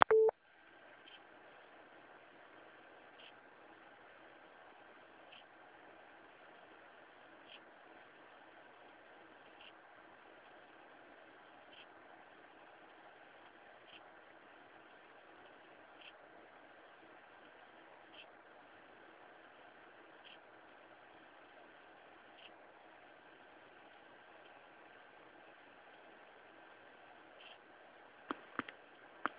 Chłodzenie nie jest bezgłośne ale jest dość ciche i nie wchodzi na wysokie obroty.
posłuchać (w tle słychać chrobotanie dysku).